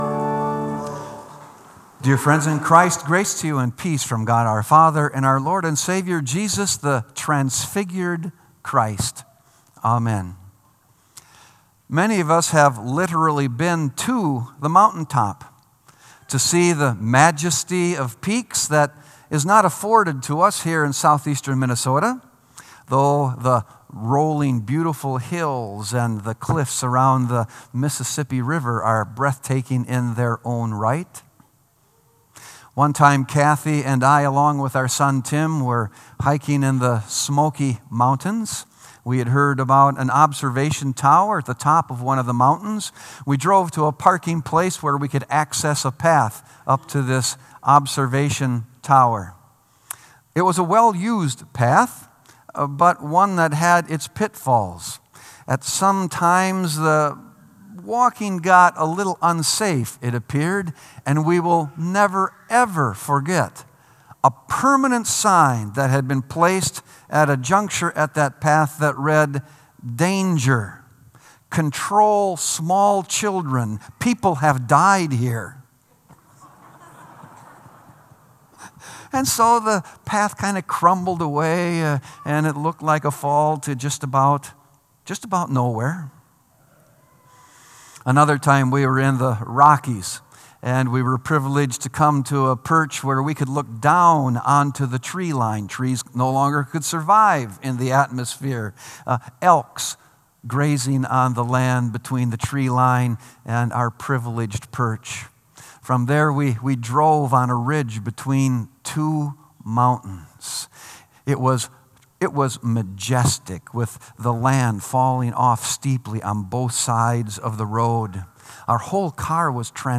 Sermon “On the Mountaintop”